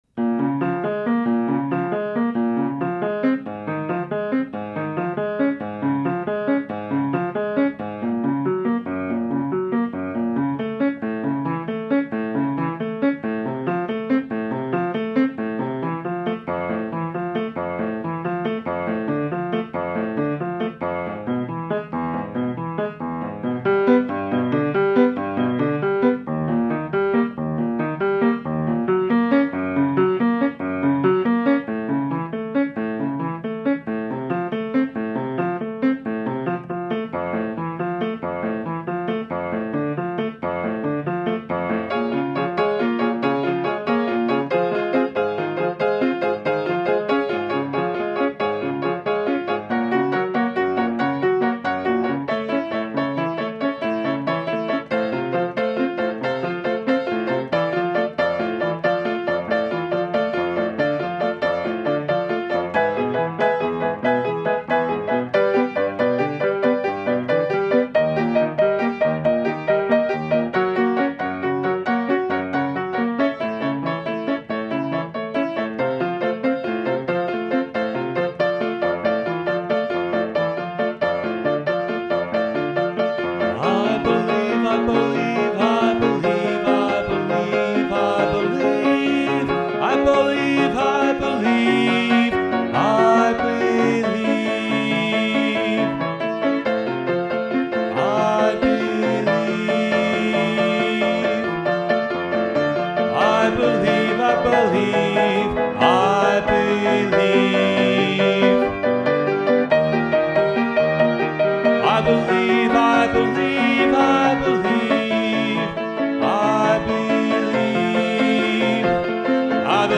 rock opera
piano and voice